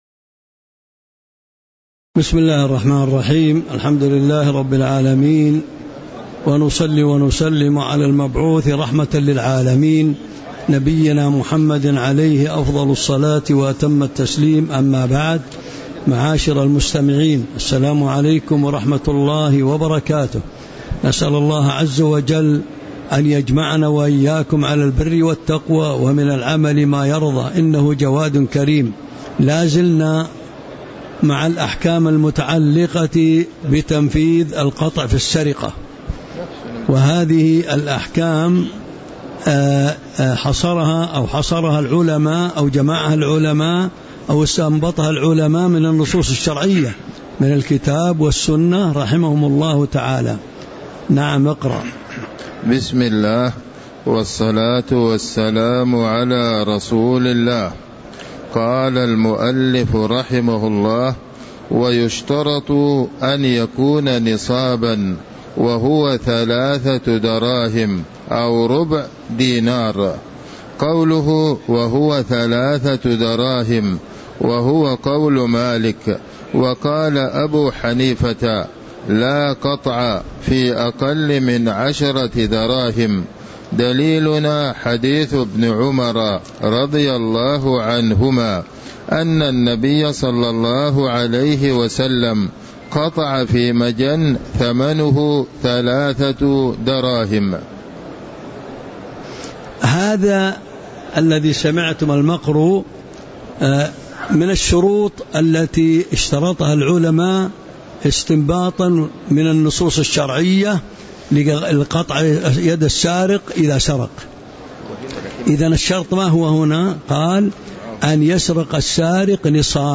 تاريخ النشر ٢٥ صفر ١٤٤٥ هـ المكان: المسجد النبوي الشيخ